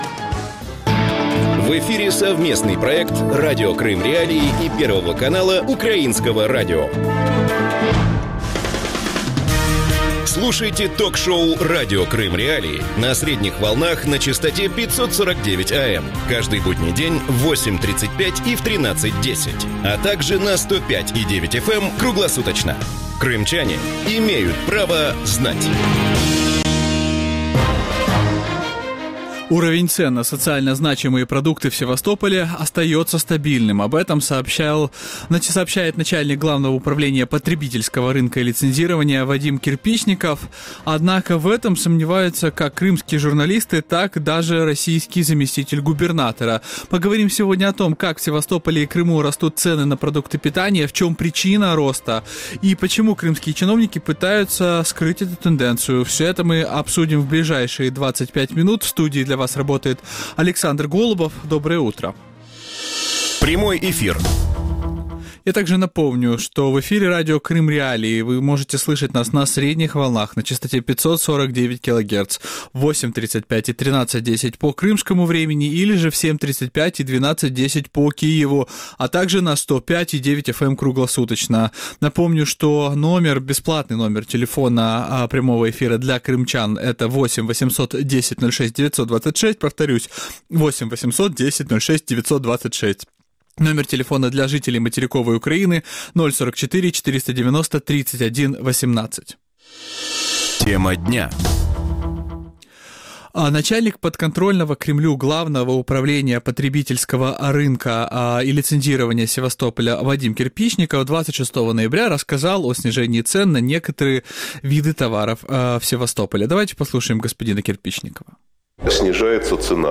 И почему крымские чиновники пытаются скрыть эту тенденцию? Гости эфира